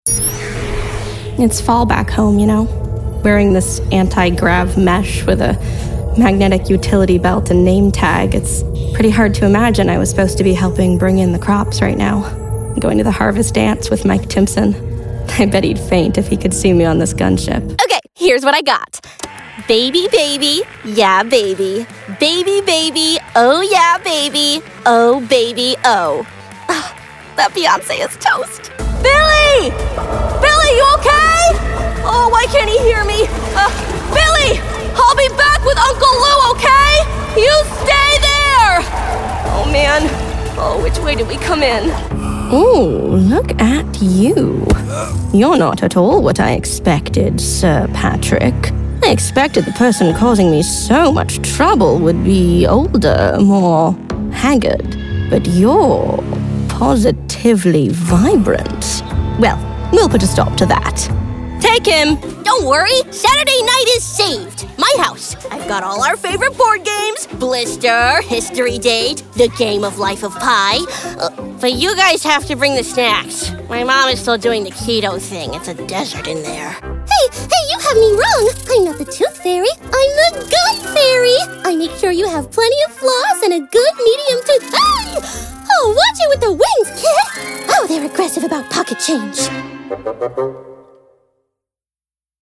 Engels (Amerikaans)
Jong, Natuurlijk, Stoer, Vriendelijk, Speels